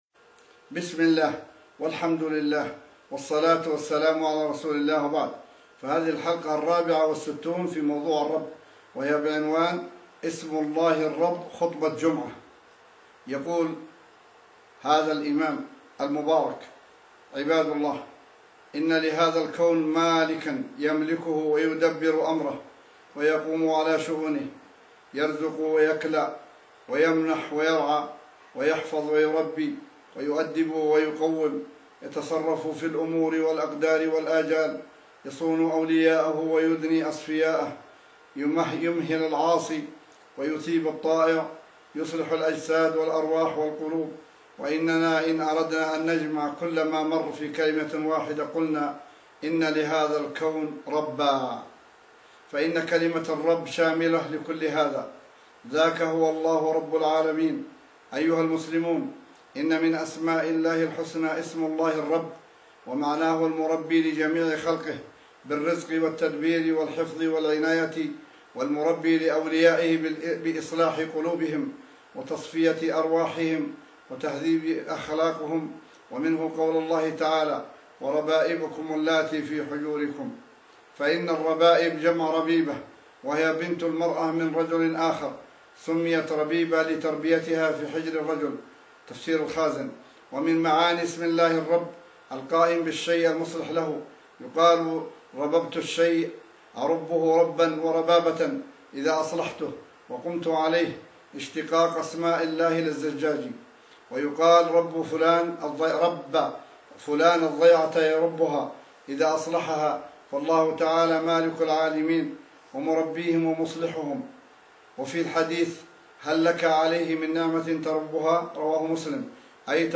بسم الله والحمد لله والصلاة والسلام على رسول الله وبعد: فهذه الحلقة الرابعة والستون في موضوع (الرب) وهي بعنوان: *اسم الله الرب- خطبة جمعة